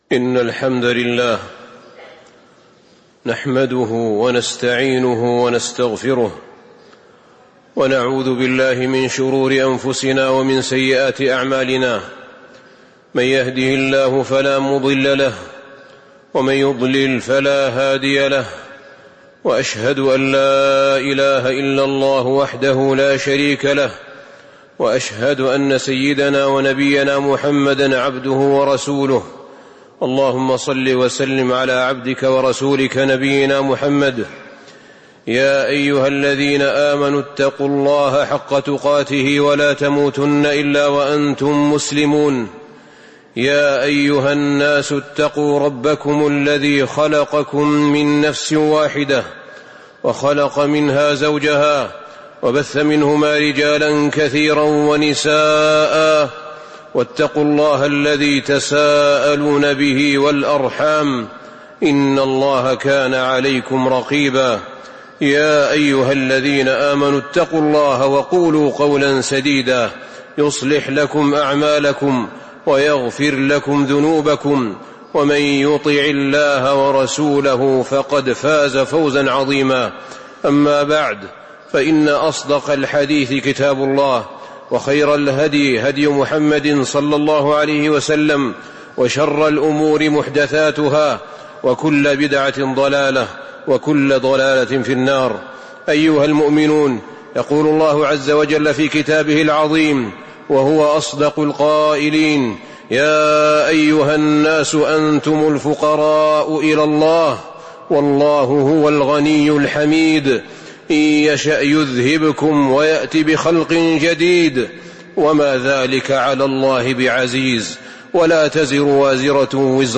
تاريخ النشر ٨ شوال ١٤٤٤ هـ المكان: المسجد النبوي الشيخ: فضيلة الشيخ أحمد بن طالب بن حميد فضيلة الشيخ أحمد بن طالب بن حميد حسن التوكل على الله